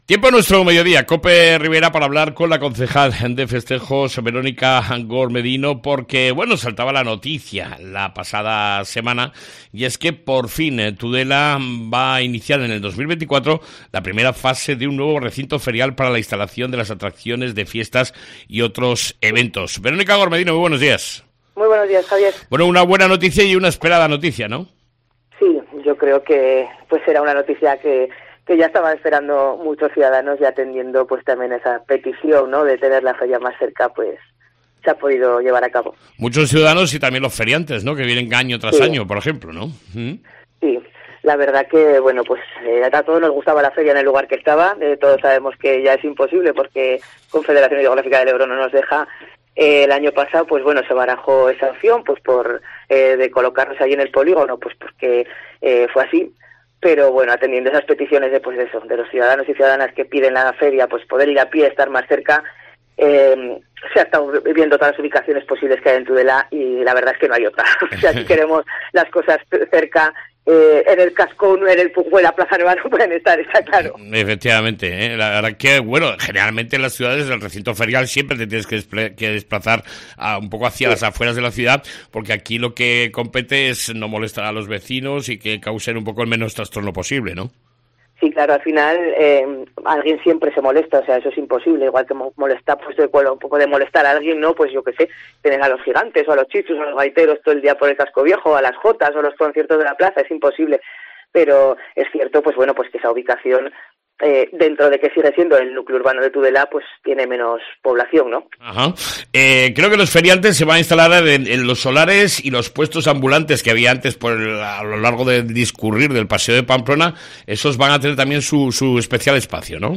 ENTREVISTA CON VERONICA GORMEDINO, CONCEJAL DE FESTEJOS